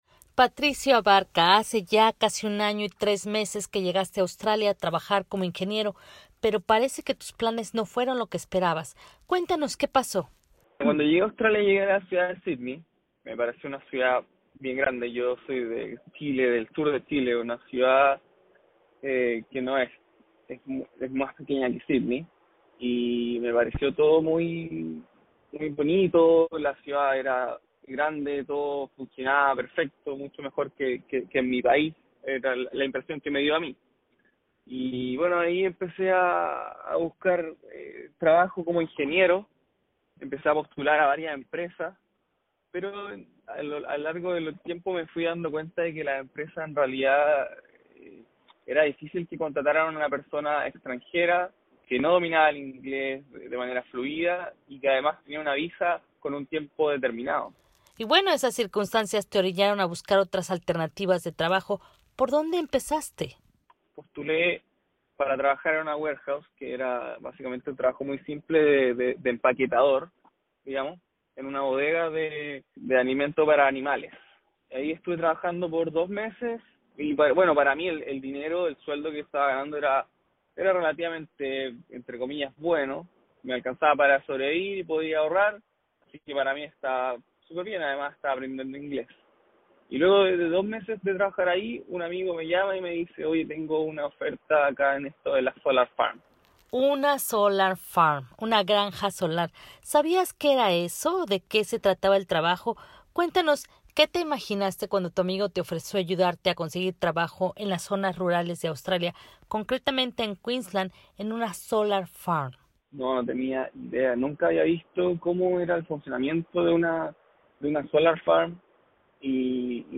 En esta entrevista